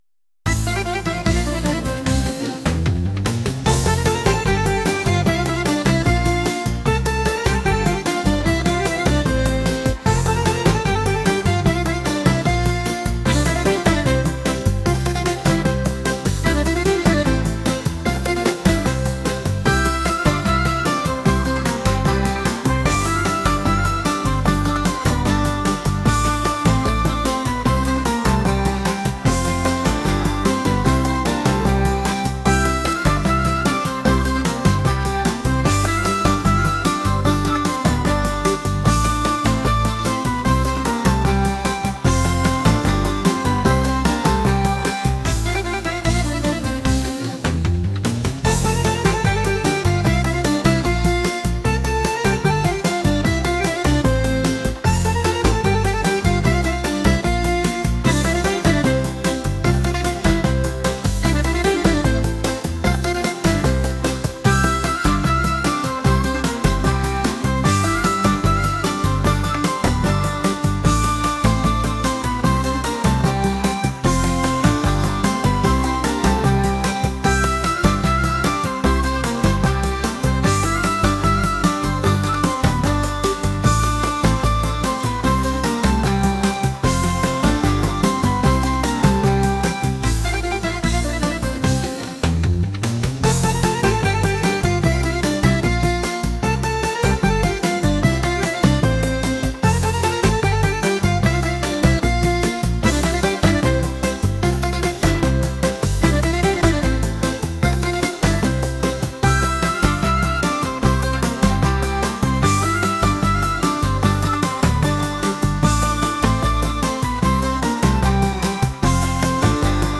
pop | upbeat